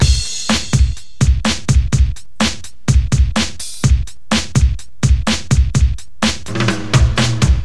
• 125 Bpm Modern Hip-Hop Drum Loop C Key.wav
Free drum loop sample - kick tuned to the C note. Loudest frequency: 1534Hz
125-bpm-modern-hip-hop-drum-loop-c-key-r6h.wav